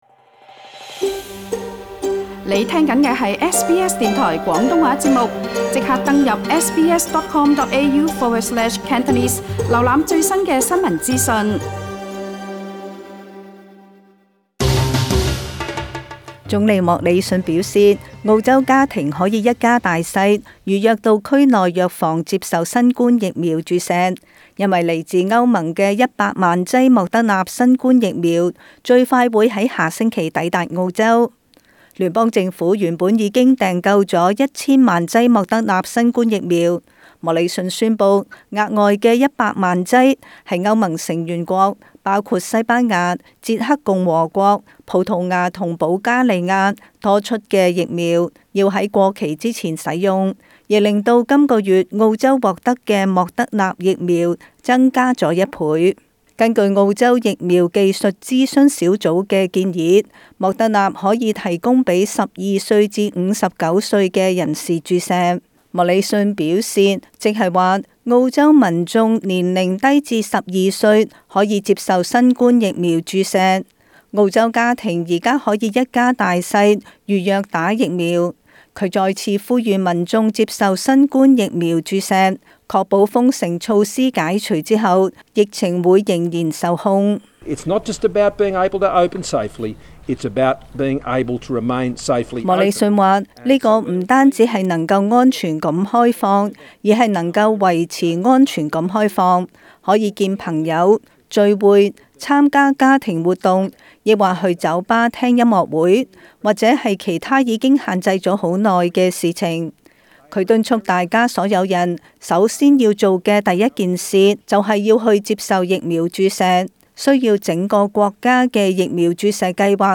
時事報道